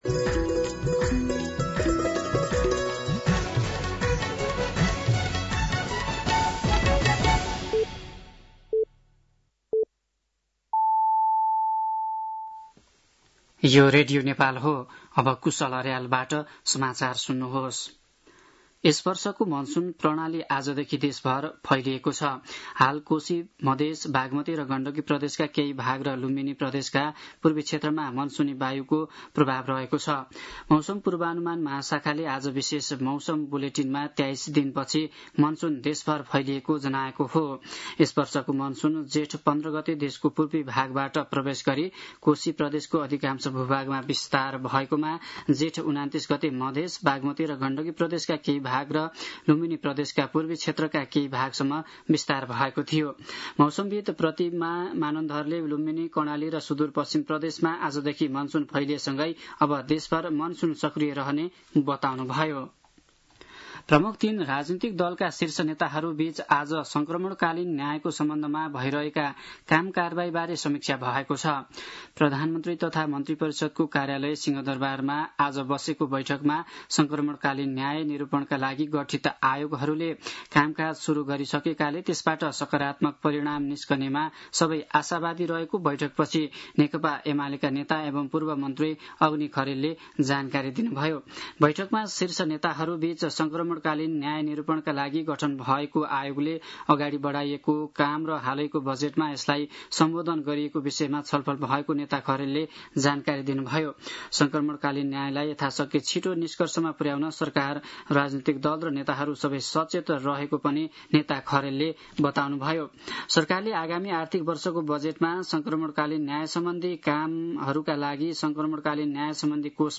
साँझ ५ बजेको नेपाली समाचार : ६ असार , २०८२